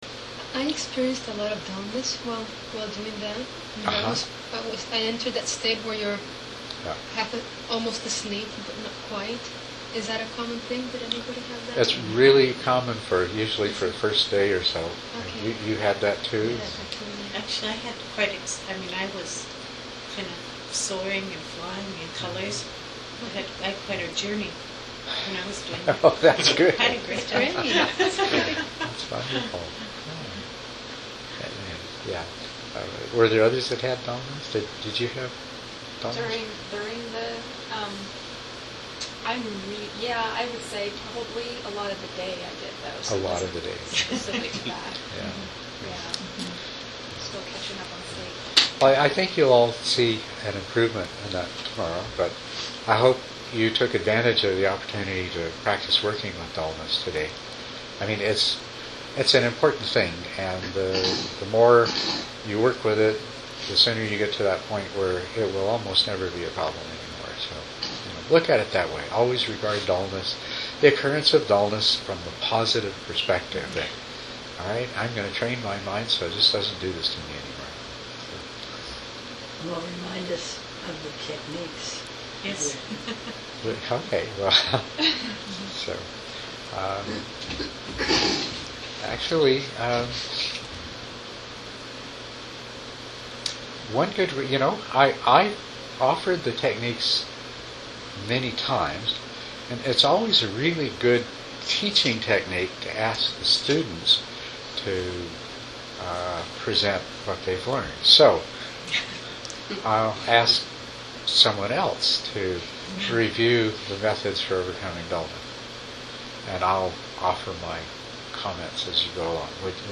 Saturday, Dharma Talk #1 Play the recording below or right click here to save to your computer.